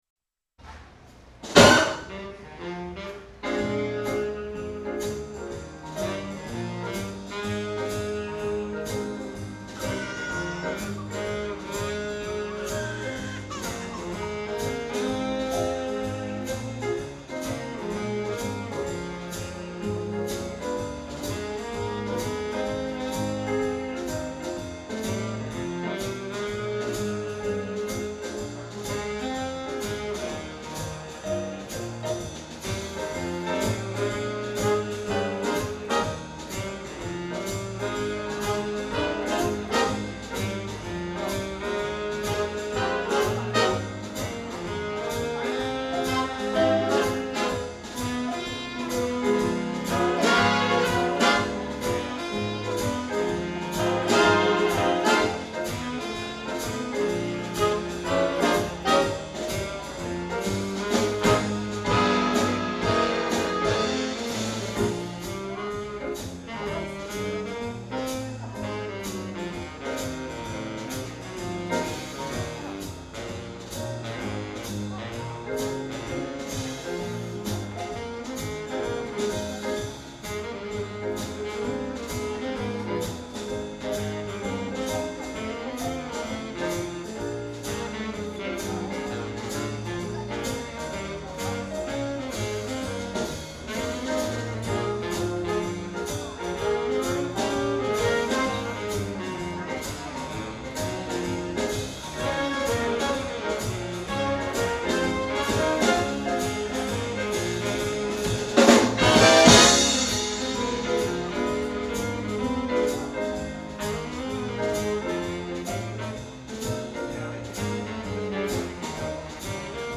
Swing
live recording